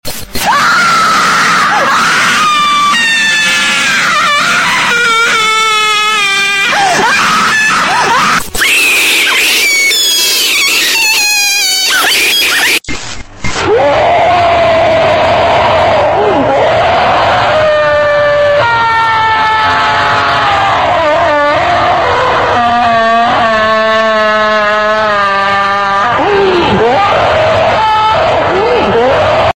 the chewing face sound effects free download